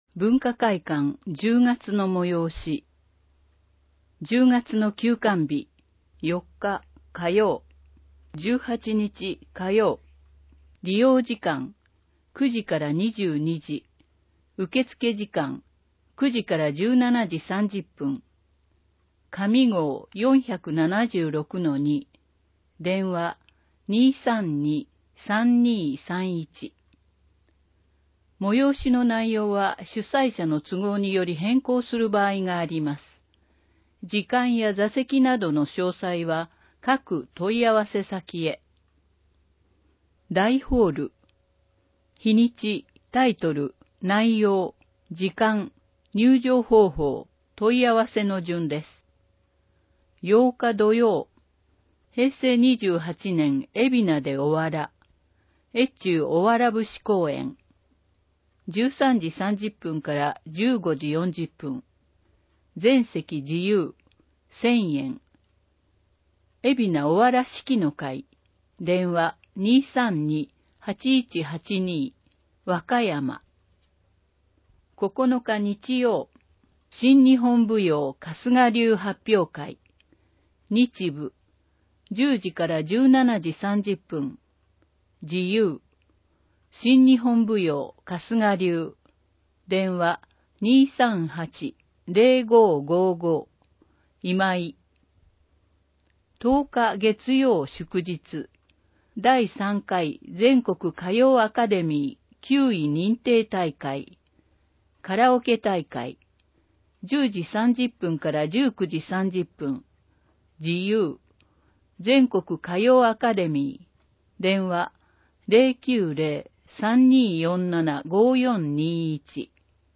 広報えびな 平成28年9月15日号（電子ブック） （外部リンク） PDF・音声版 ※音声版は、音声訳ボランティア「矢ぐるまの会」の協力により、同会が視覚障がい者の方のために作成したものを登載しています。